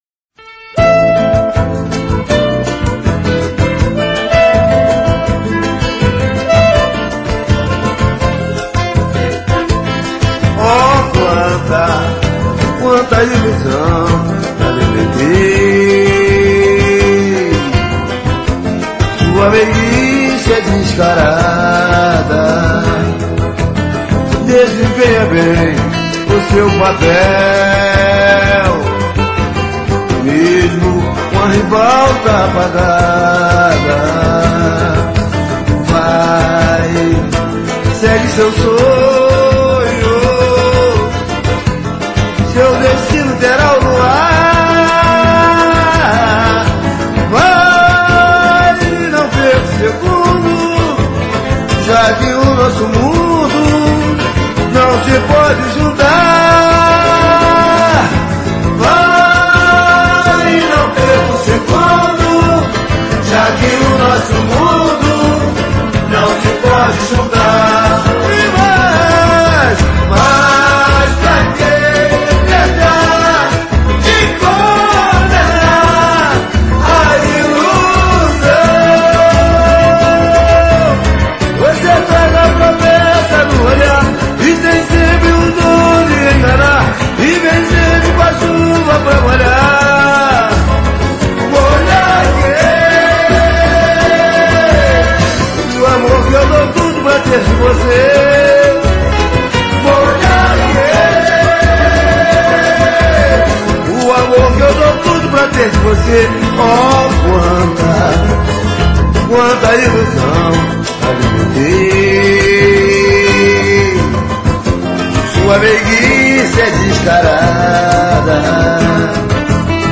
Sambas